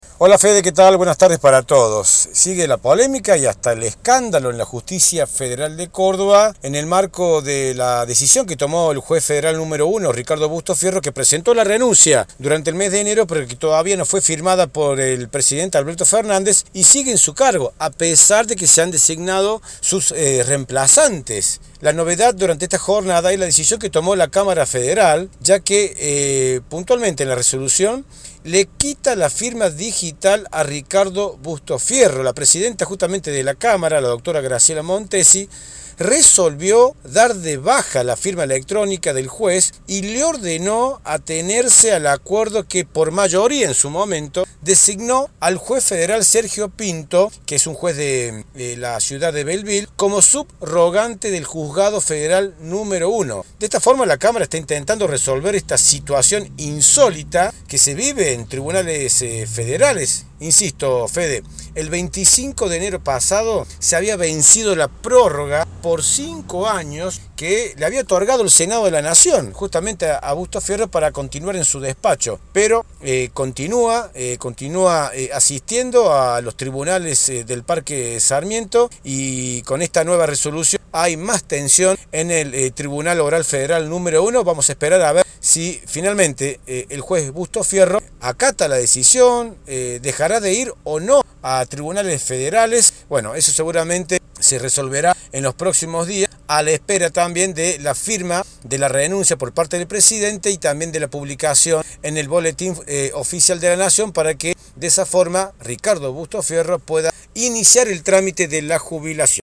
Síntesis de noticias
Informe